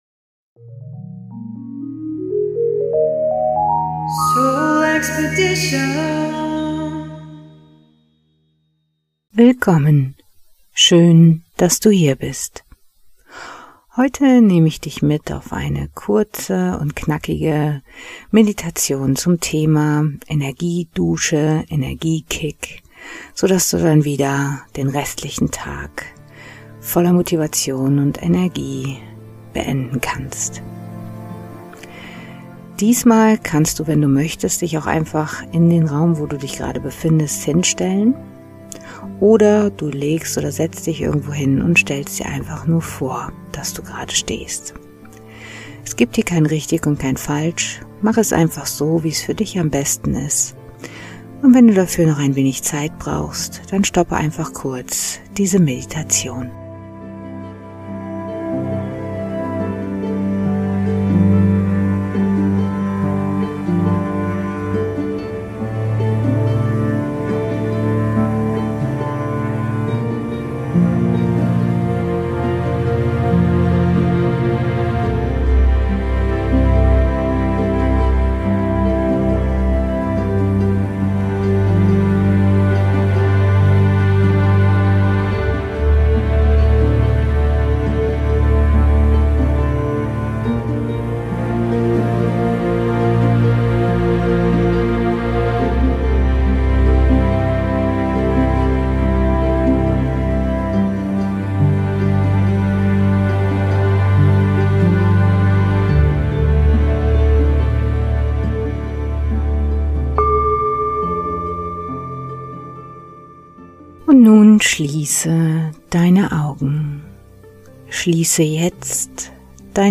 Dann ist diese Meditation/Hypnose genau das Richtige für Dich.